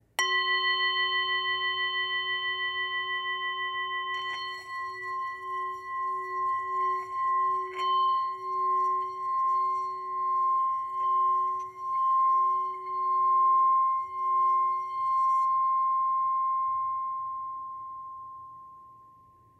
Tepaná tibetská mísa Go o hmotnosti 312g, včetně paličky
Zvuk tibetské misy Go si můžete poslechnout zde
tibetska_misa_m43.mp3